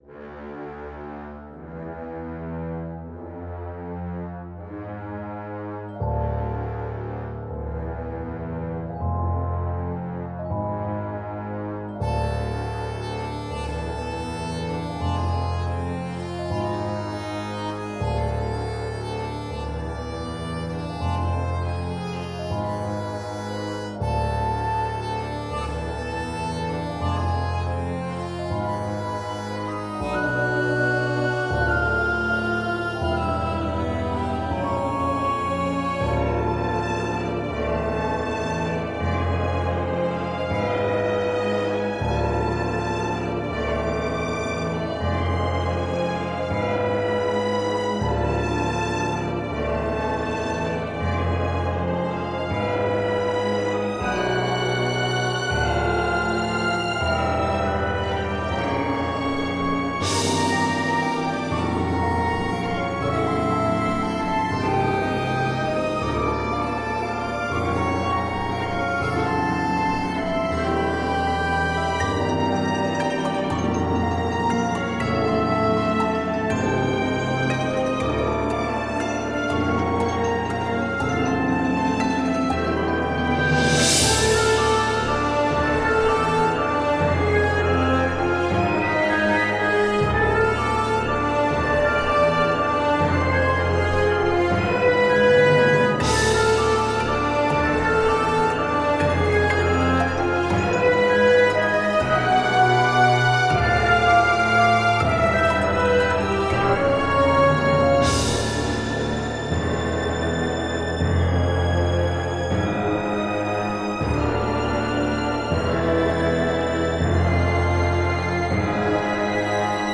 Inquiétant.